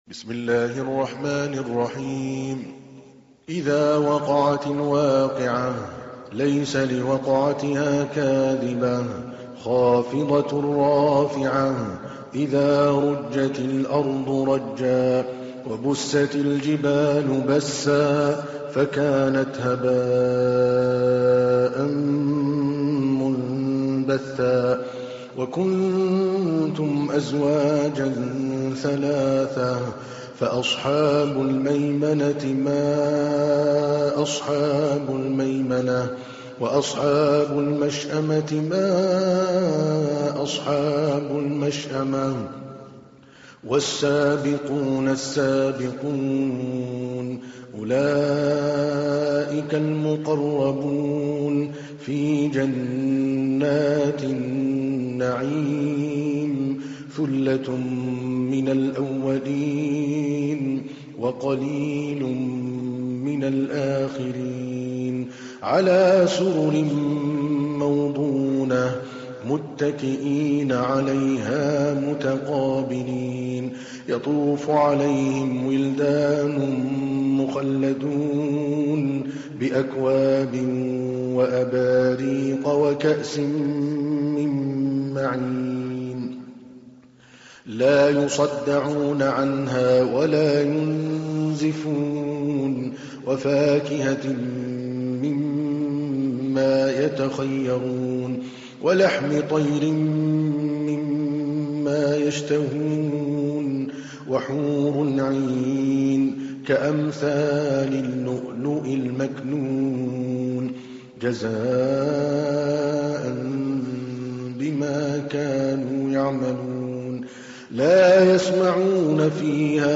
تحميل : 56. سورة الواقعة / القارئ عادل الكلباني / القرآن الكريم / موقع يا حسين